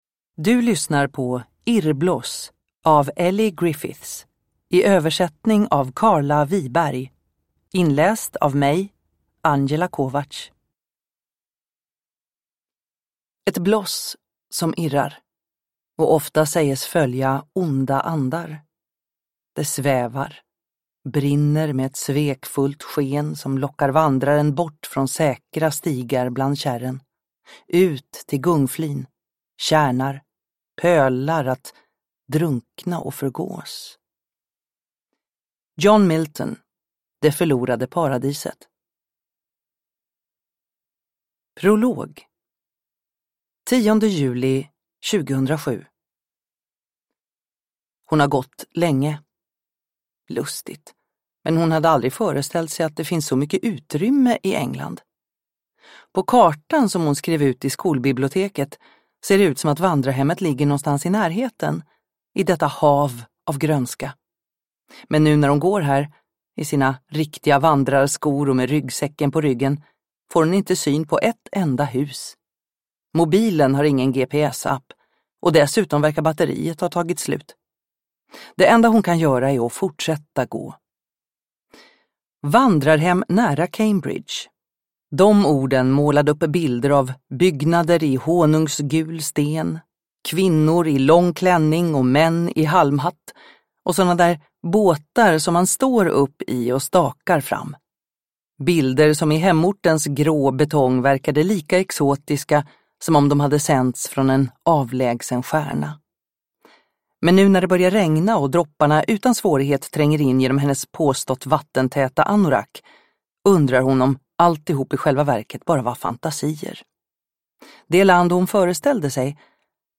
Irrbloss – Ljudbok – Laddas ner